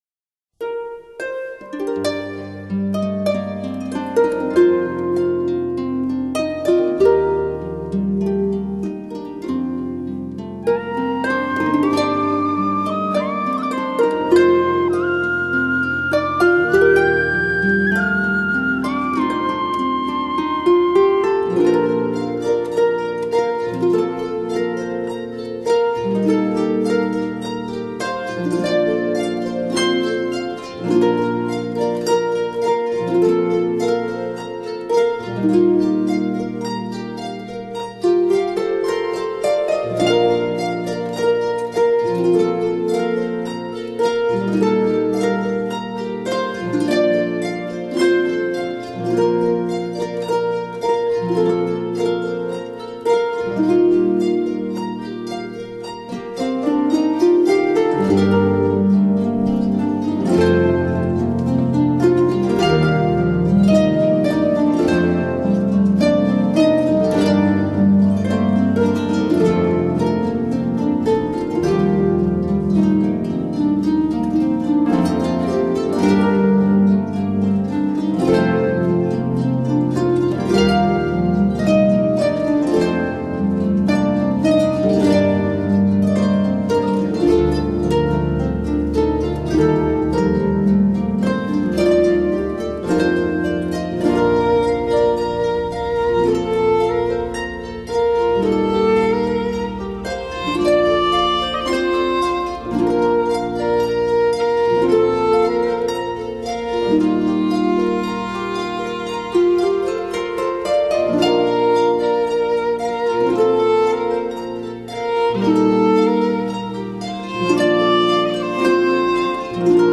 Celtic, Instrumental